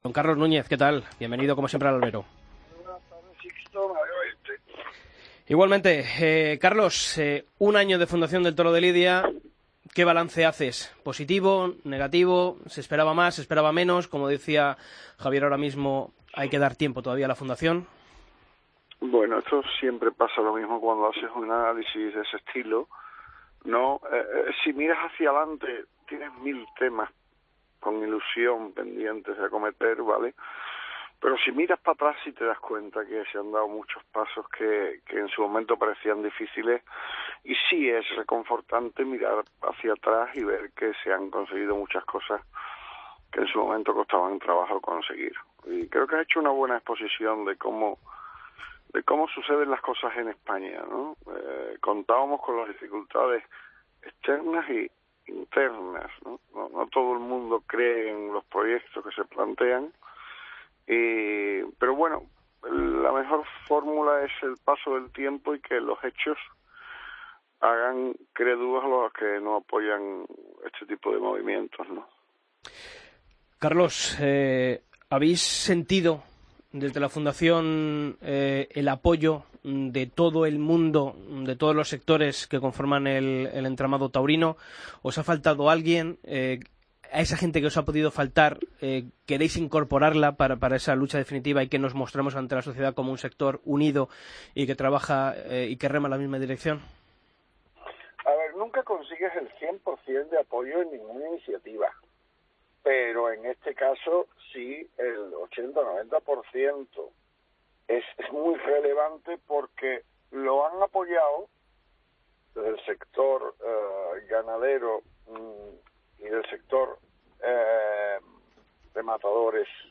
Escucha la entrevista a Carlos Núñez en El Albero